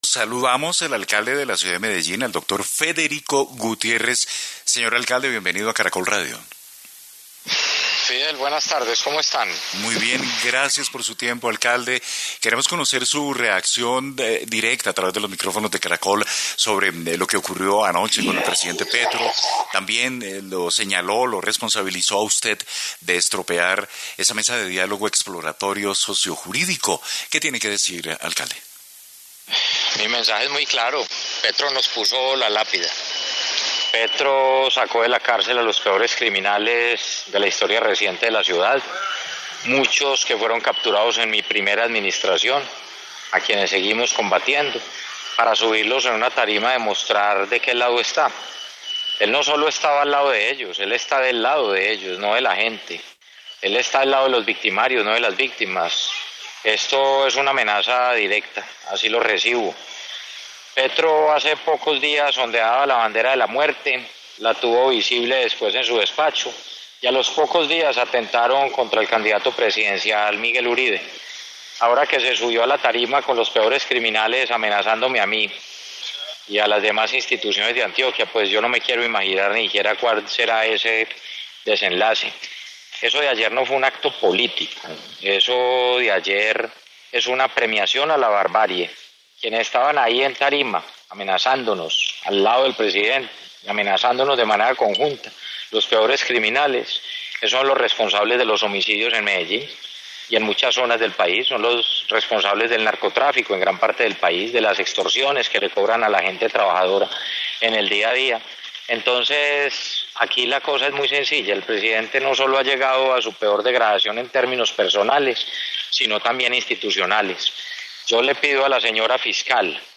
En entrevista con Caracol Radio, el Alcalde de Medellín, Federico Gutiérrez, señaló que la presencia de criminales en acto del presidente es una amenaza.